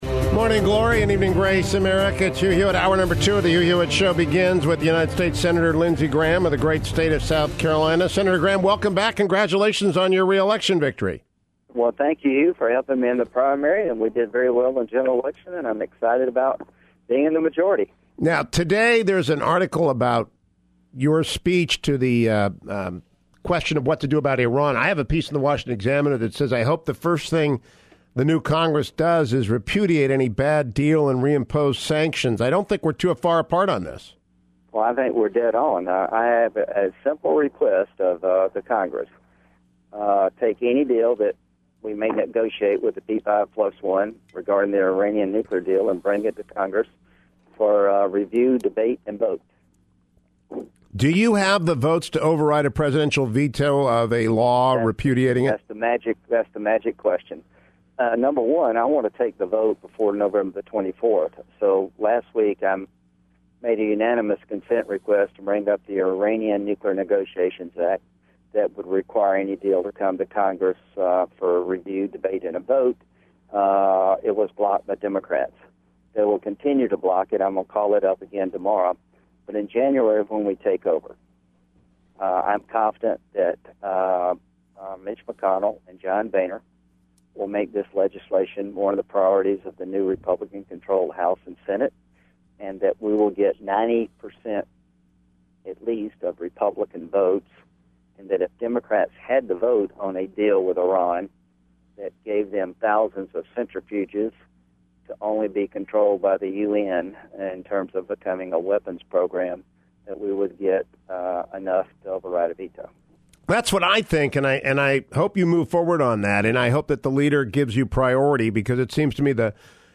South Carolina Senator Lindsey Graham joined me on today’s show and we covered a half-dozen key subjects: